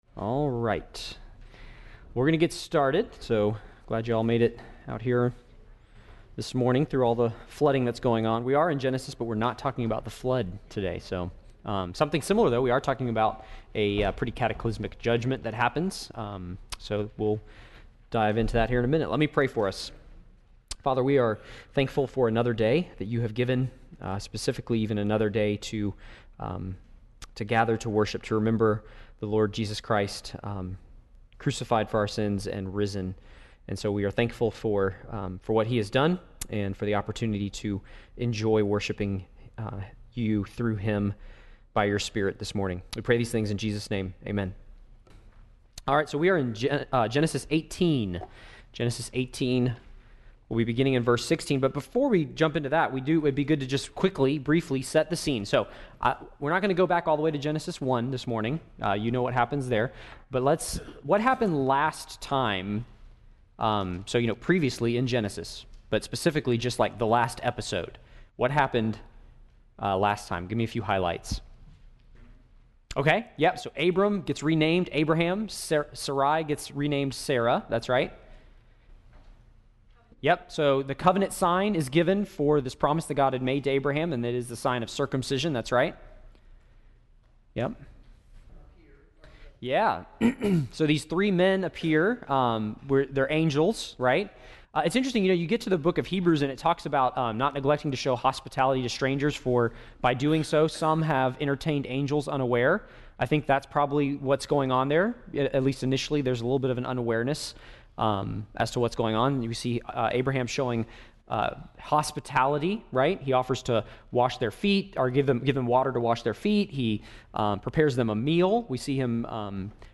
Lesson 29 in the Genesis: Foundations Sunday School class.